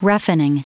Prononciation du mot roughening en anglais (fichier audio)
Prononciation du mot : roughening